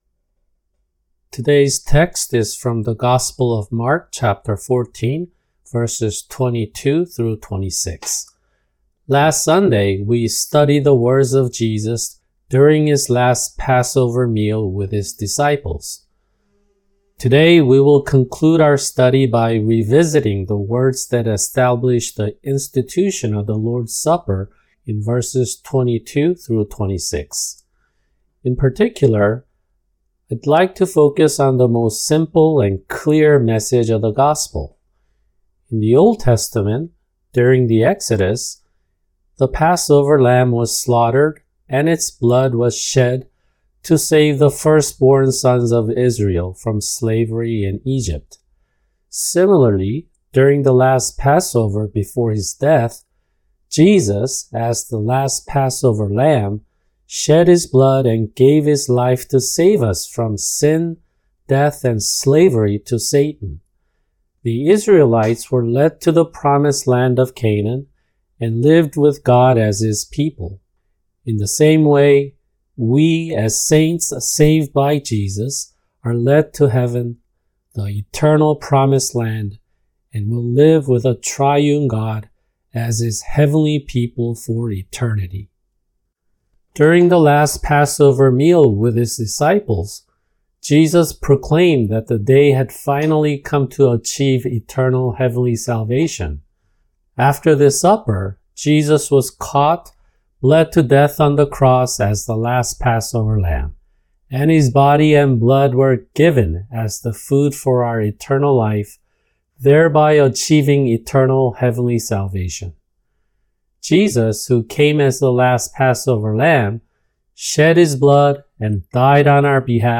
[주일 설교] 마가복음(65) 14:22-26
[English Translation] Mark(65) 14:22-26 – Audio